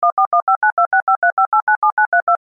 Sound Effects Library. Telephone’s Touch Keys (CC BY-SA)
sound-effects-library-telephones-touch-keys.mp3